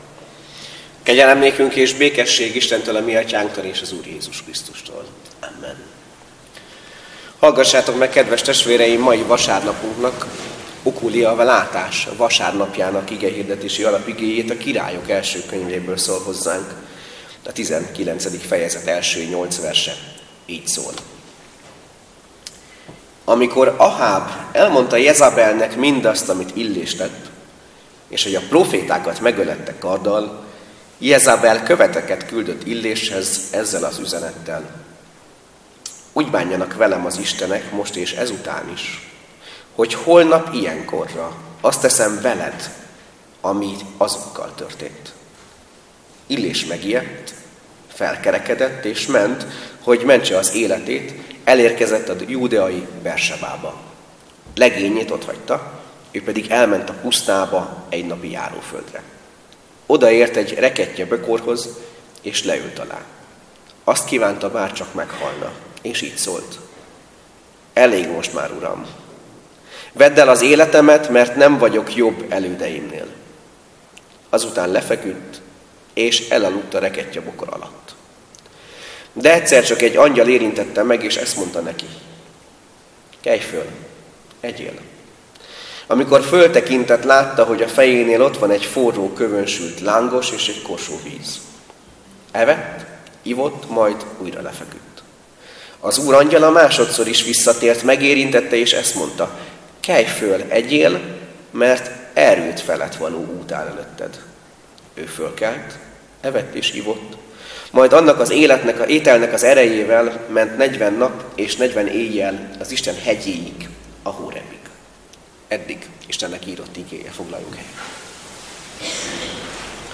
(Zsolt 25,15) - (Szerkesztői megjegyzés: A felvétel közepén néhány perc hiányzik, fogadjátok megértéssel.)